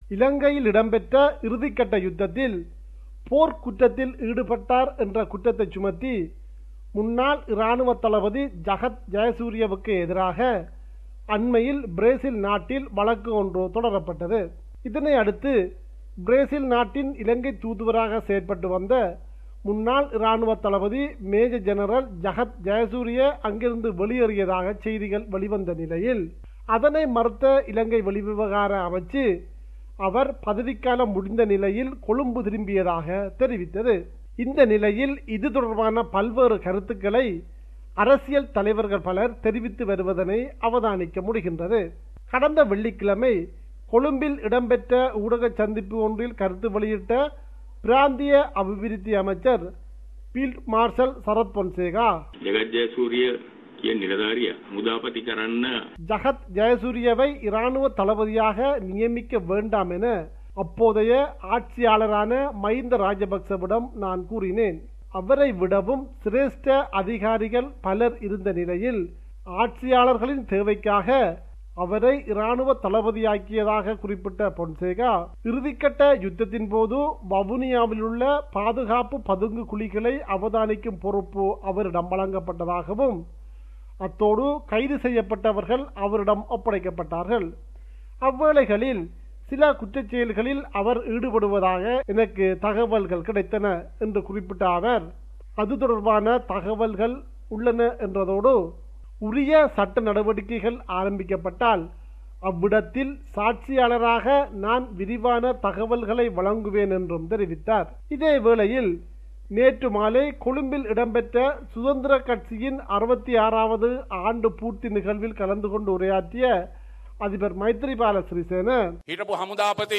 compiled a report focusing on major events in Sri Lanka.